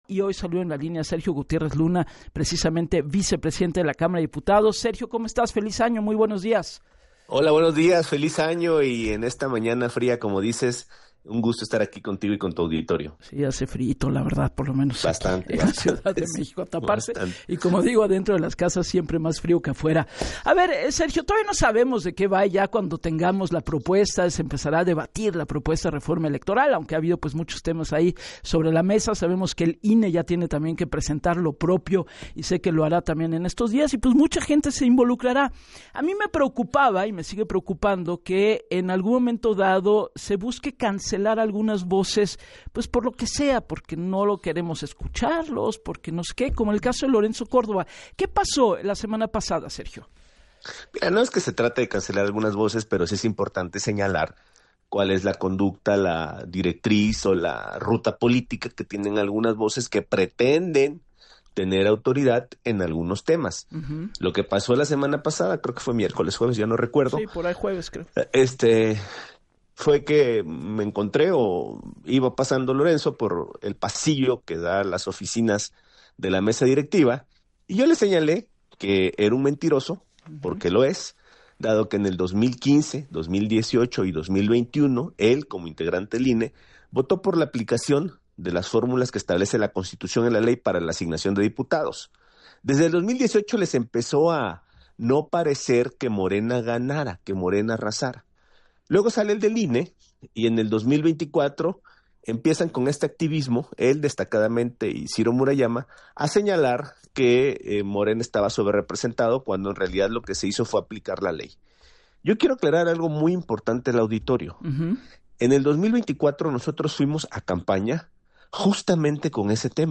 En entrevista para “Así las Cosas” con Gabriela Warkentin, el también vicepresidente de la mesa directiva de la Cámara de Diputados, aseguró que cuando se inicie el debate de la reforma electoral “todos pueden hablar… eso ha sucedido y seguirá sucediendo, podrá no gustarnos o no lo que digamos”, luego de tener un intercambio e palabras con el expresidente consejero del INE, Lorenzó Córdova.